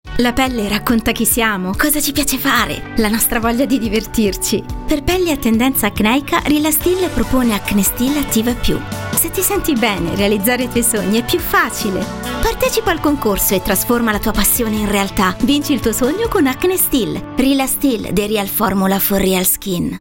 Demo
Spot tv